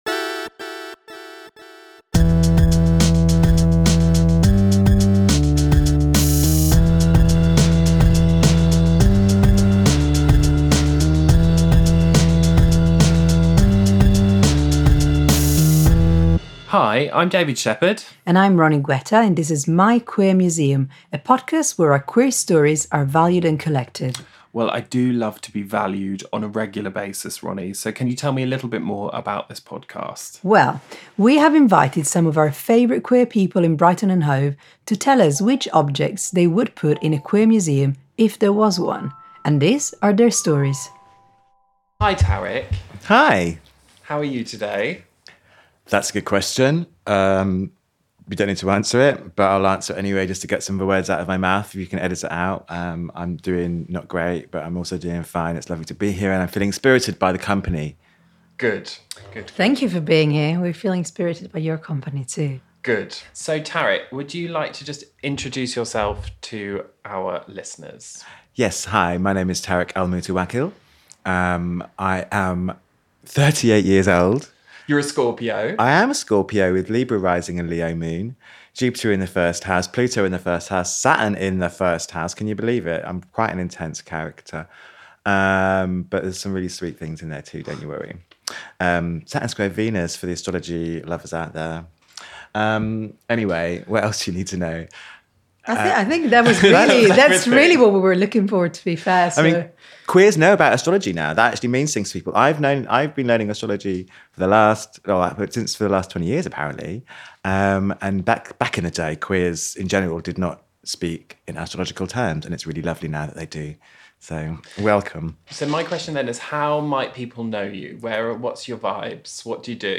Spatial Coverage Brighton UK